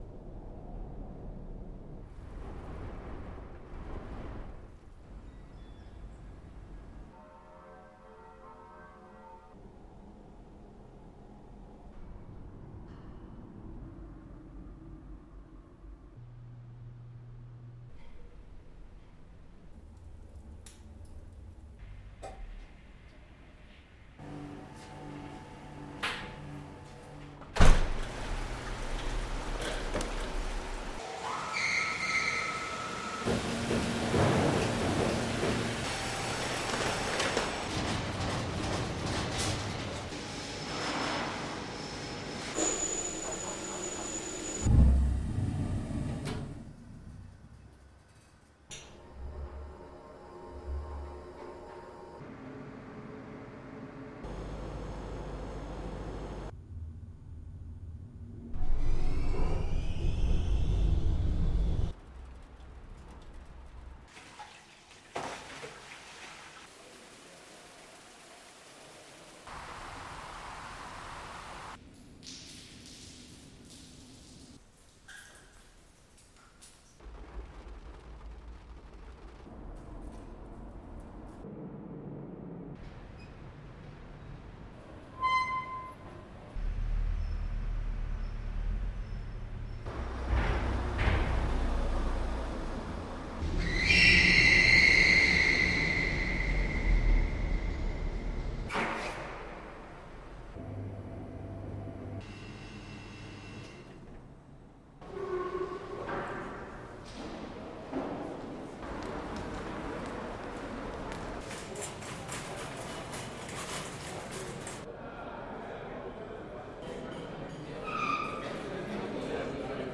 声 道 数: 2 channels